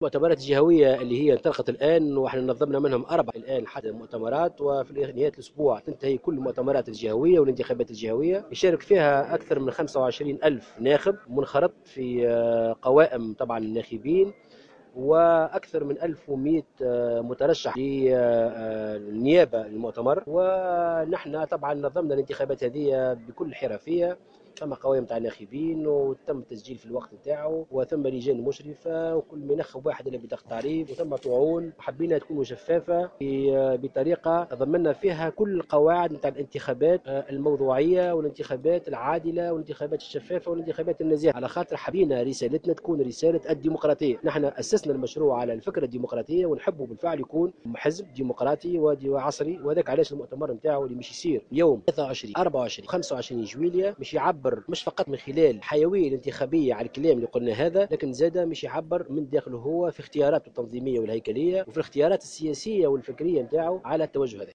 يعقد حزب "حركة مشروع تونس" مؤتمره التأسيسي الأول، أيام 23 و24 و25 جويلية القادم ، حسب ما أعلن عنه اليوم الخميس محسن مرزوق المنسق العام للحركة اليوم الخميس، خلال ندوة صحفية .